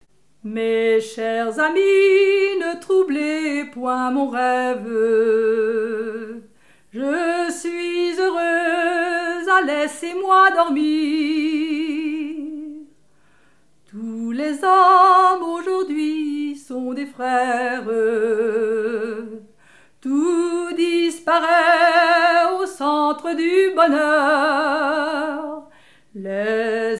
Mémoires et Patrimoines vivants - RaddO est une base de données d'archives iconographiques et sonores.
Genre strophique
la danse la ridée et chansons
Pièce musicale inédite